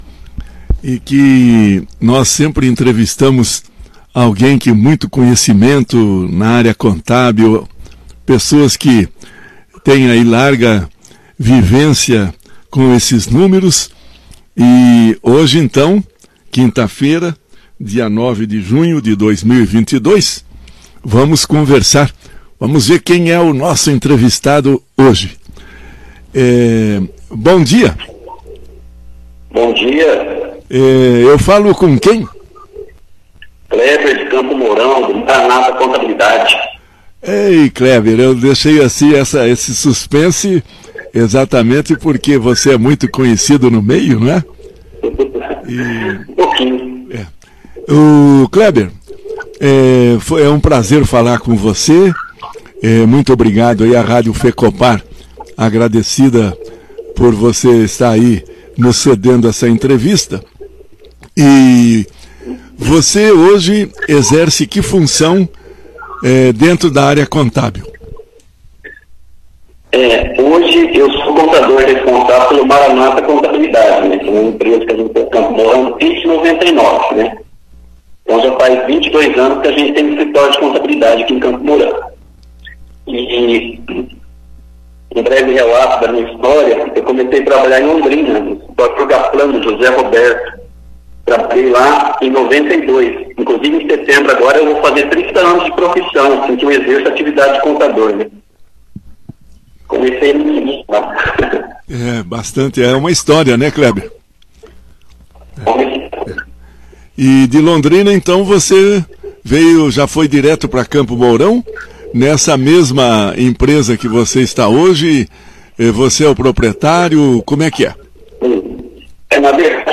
Confira a entrevista na íntegra que foi ao ar nesta quinta-feira (09):